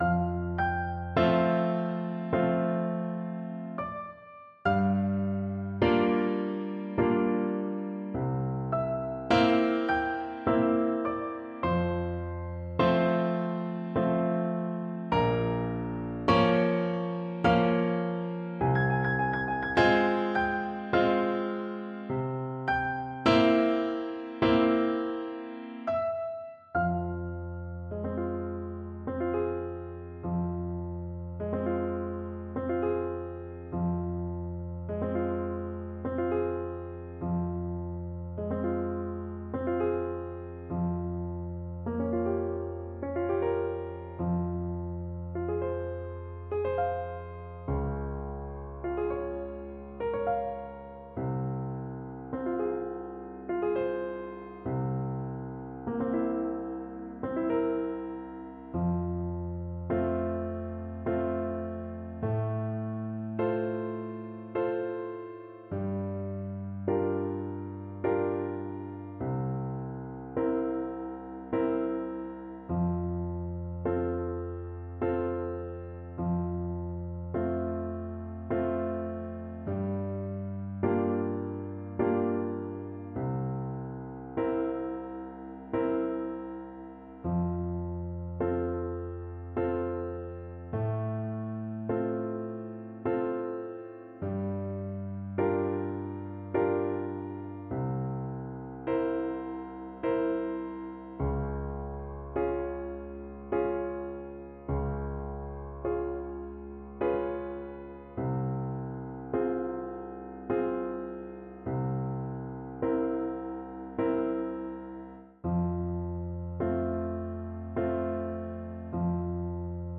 3/4 (View more 3/4 Music)
Andantino = c. 86 (View more music marked Andantino)
Neapolitan Songs for Clarinet